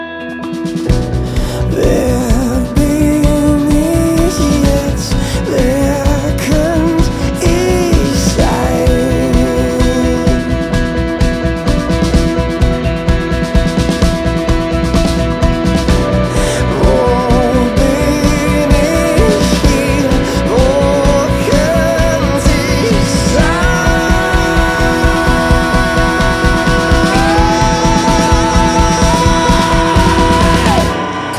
Gesang, Gitarre, Klavier und Backings
eine tief emotionale Atmosphäre